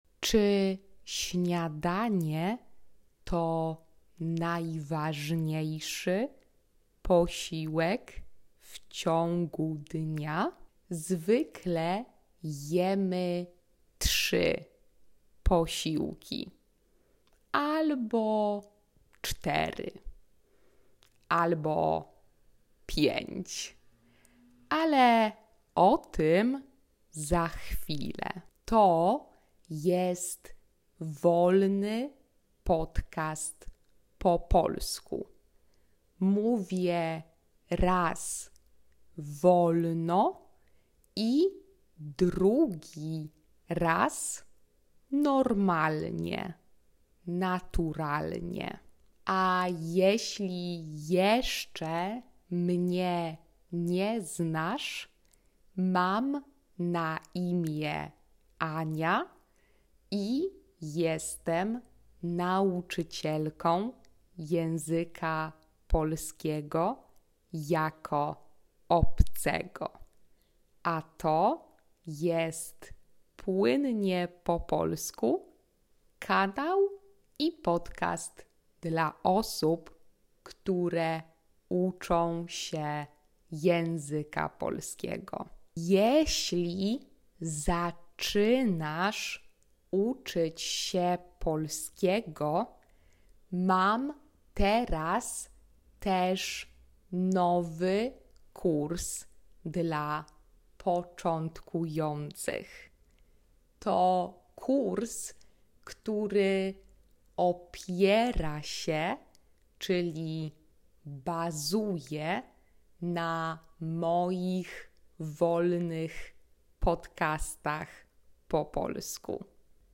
#75 Śniadania w Polsce (Slow Polish)
Slow-Polish-Podcast-breakfast.mp3